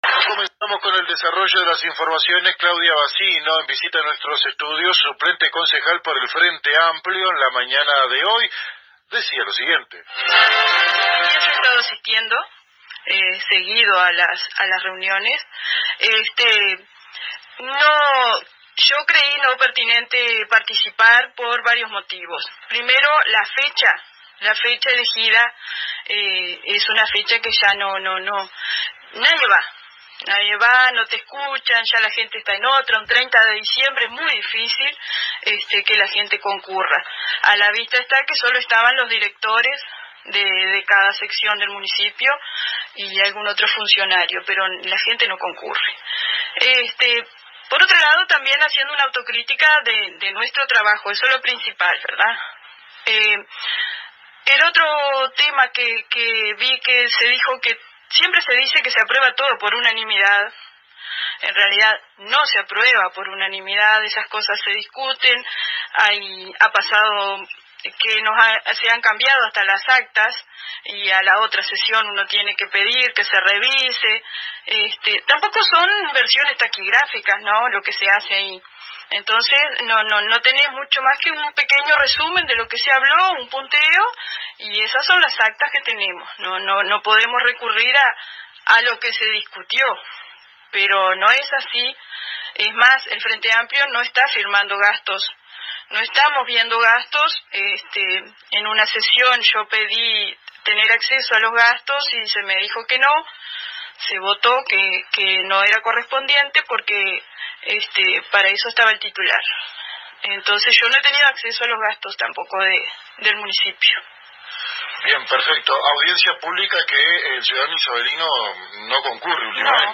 La suplente del Concejal Walter Sánchez, Claudia Bacino, visitó los estudios de la AM 1110 local y conversó con los periodistas de este medio radial isabelino, sobre diferentes temas de interés en el ámbito político de nuestra ciudad, uno de ellos referido a su ausencia en la reciente Audiencia Pública que ofreció el Municipio a principios de esta semana.